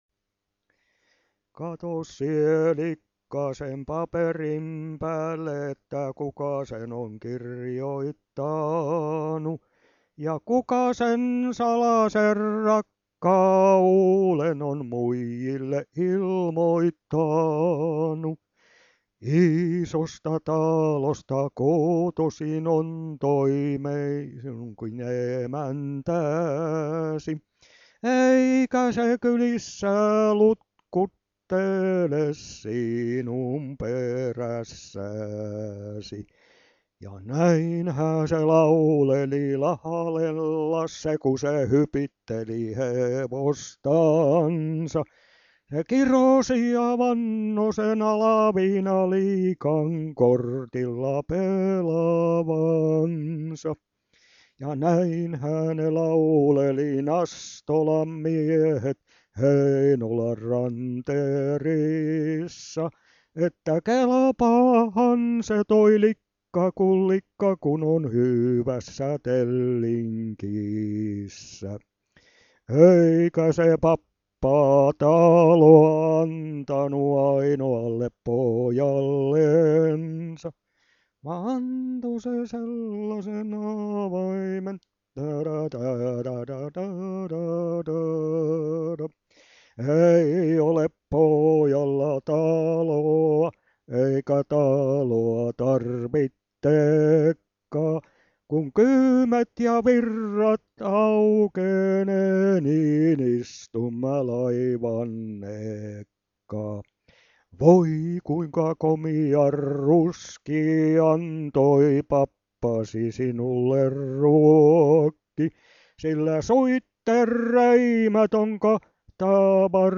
VANHOJA PIIRILEIKKILAULUJA